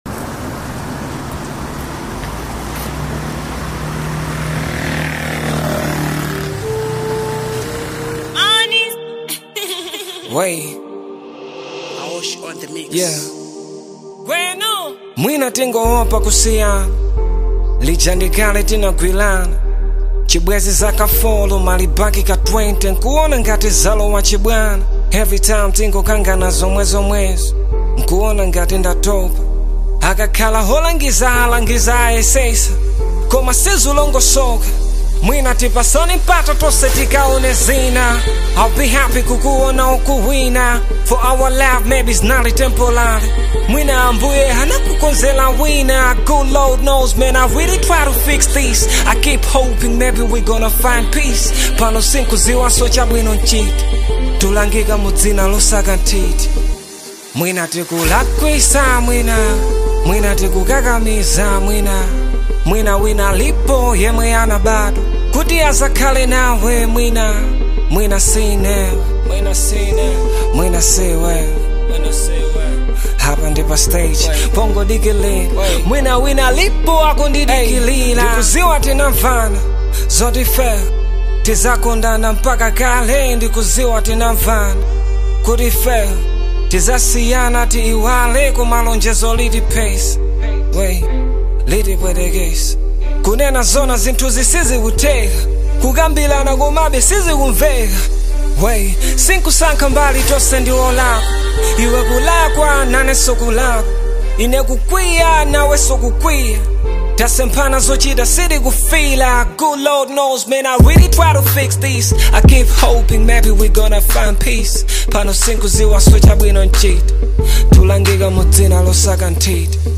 Genre: Afro-Beats, Malawi Songs